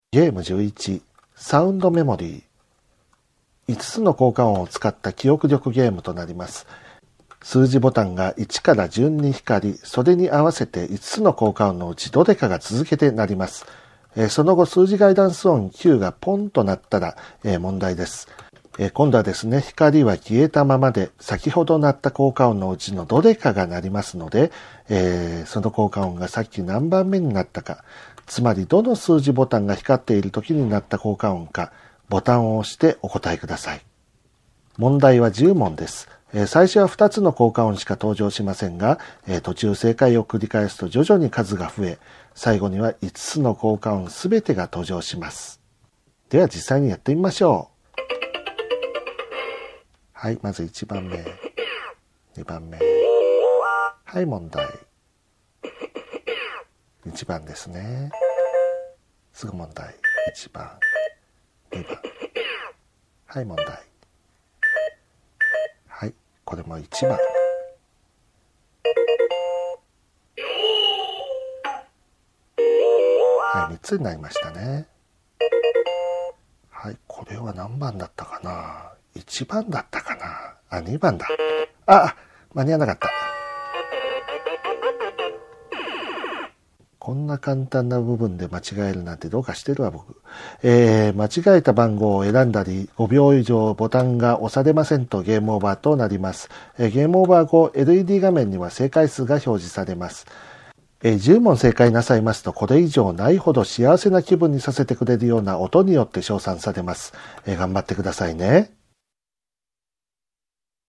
ゲームロボット５０の遊び方音声ガイド
遊び方の説明書は商品に同梱していますが、目の不自由な方にも遊んでいただけるよう音声による遊び方の説明をご用意しております。